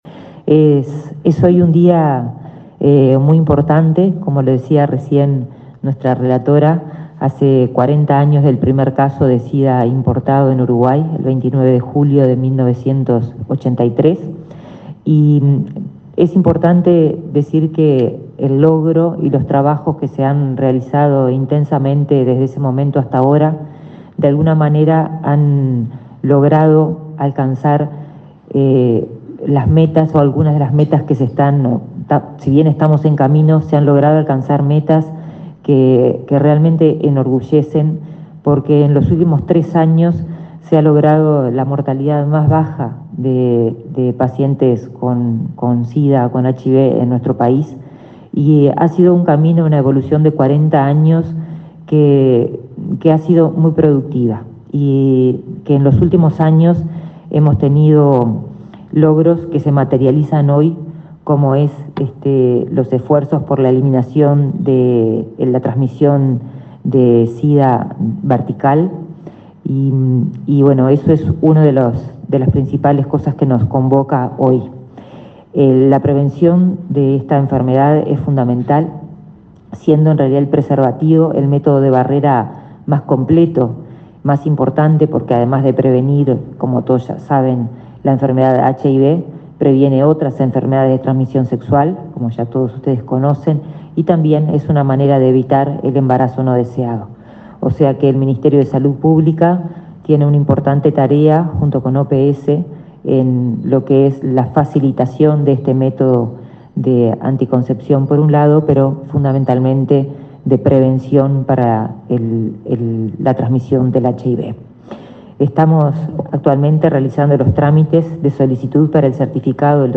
Palabras de la ministra de Salud Pública, Karina Rando
Palabras de la ministra de Salud Pública, Karina Rando 27/07/2023 Compartir Facebook X Copiar enlace WhatsApp LinkedIn Este jueves 27, en el Ministerio de Salud Pública, la titular de esa cartera, Karina Rando, presidió el acto por el Día Nacional de Lucha contra el VIH/Sida.